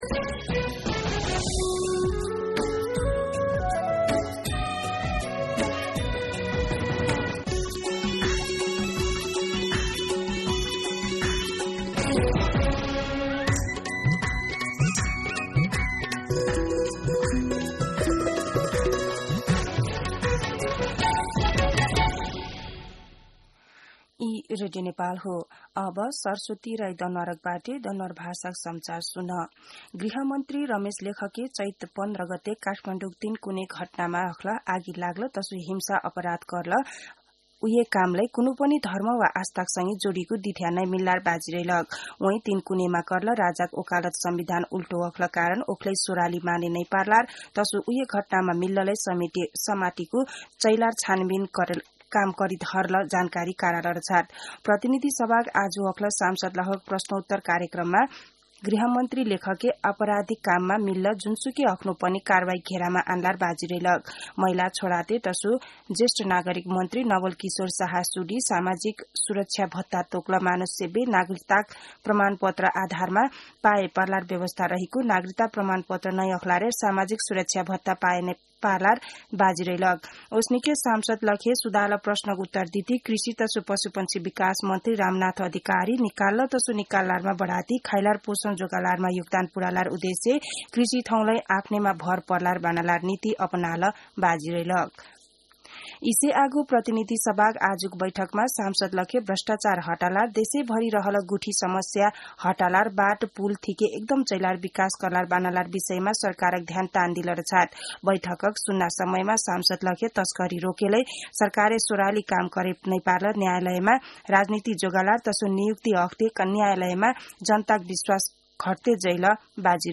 दनुवार भाषामा समाचार : ५ जेठ , २०८२